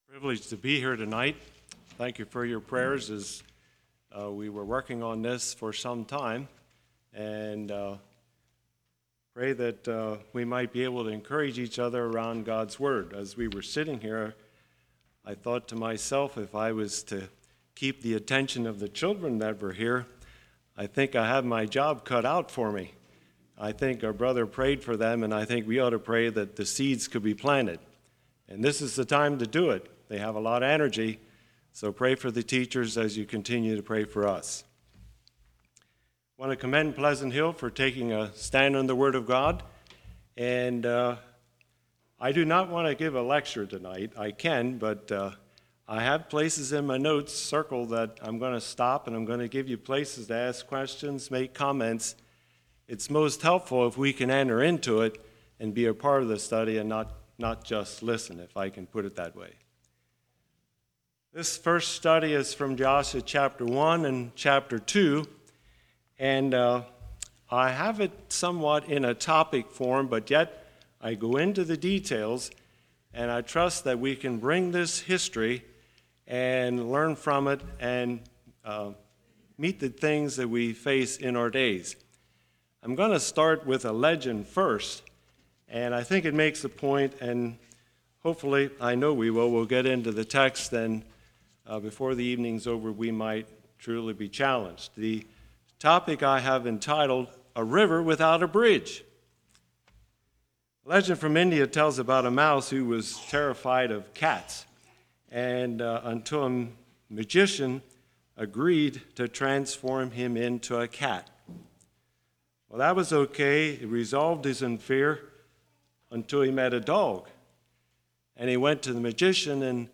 Service Type: Winter Bible Study